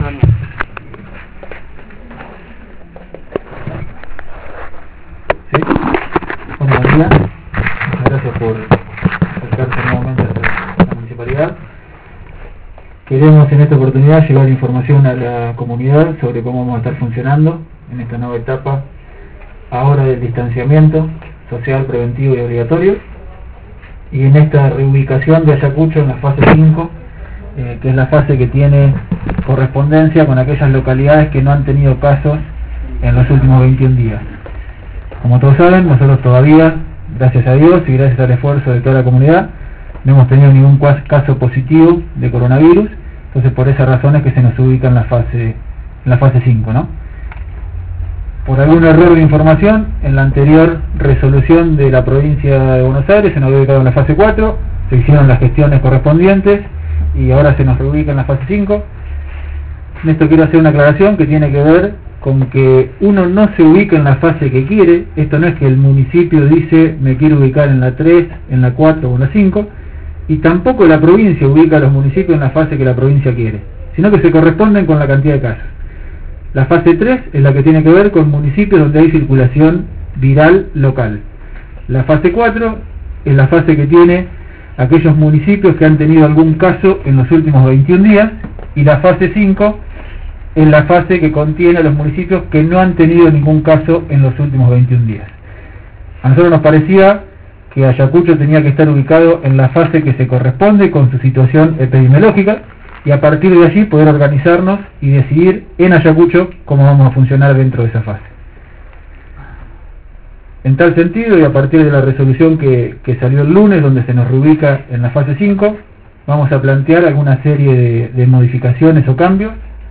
Aspectos destacados de la rueda de prensa;